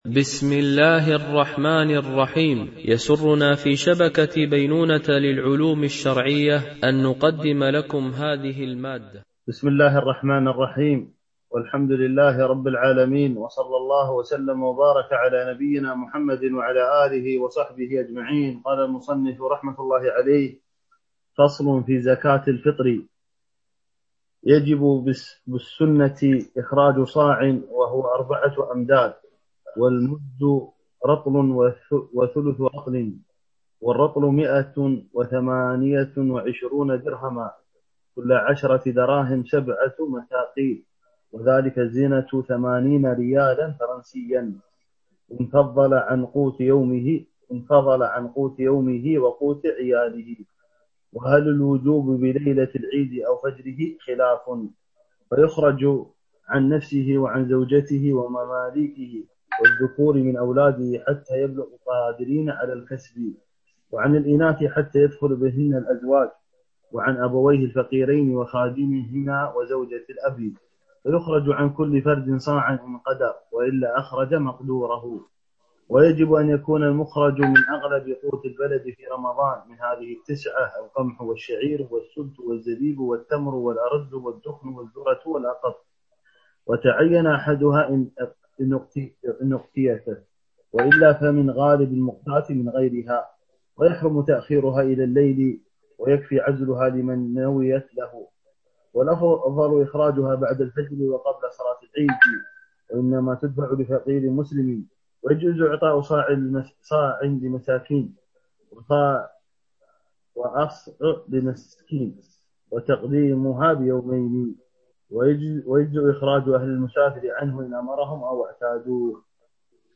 شرح الفقه المالكي ( تدريب السالك إلى أقرب المسالك) - الدرس 23 ( كتاب الزكاة )